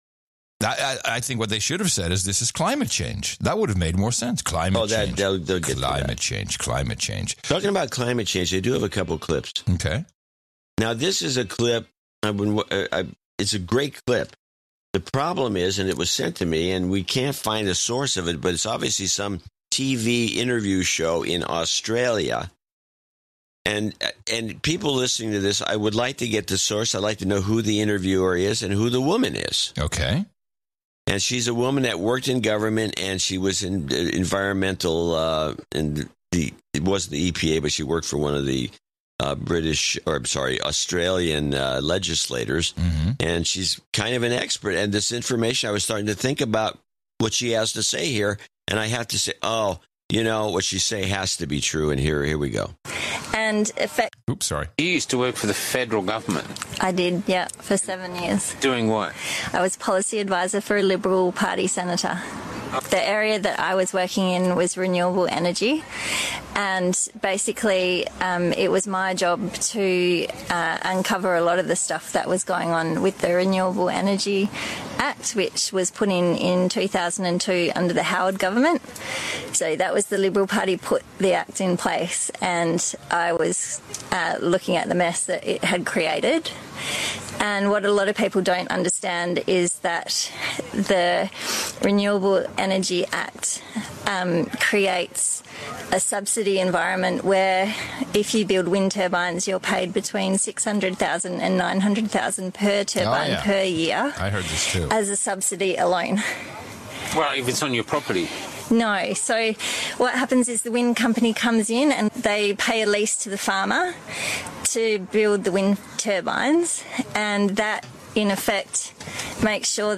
Wind turbines are a giant fraud as exposed by this Australian former policy advisor on renewable energy.